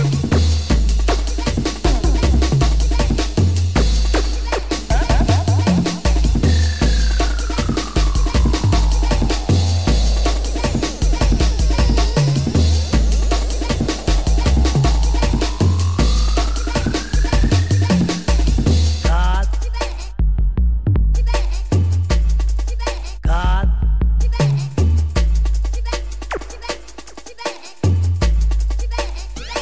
TOP > Jungle